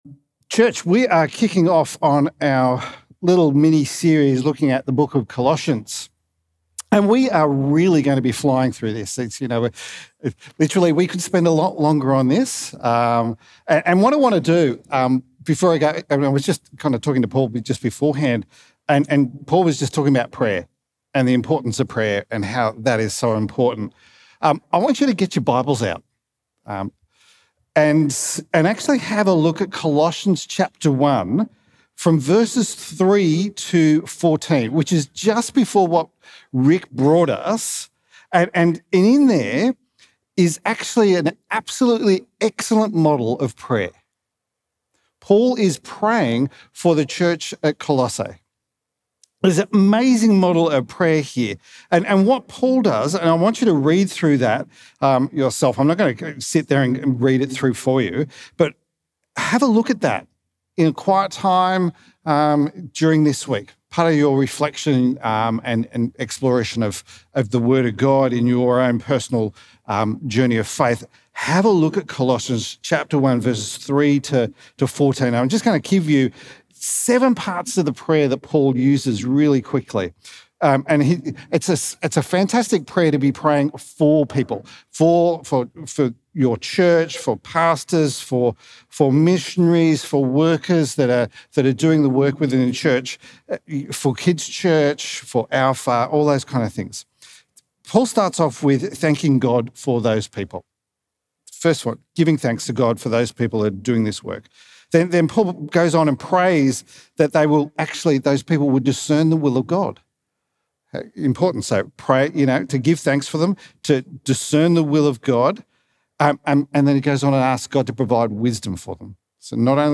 Message looking at the Christology found in Colossians 1:15-23.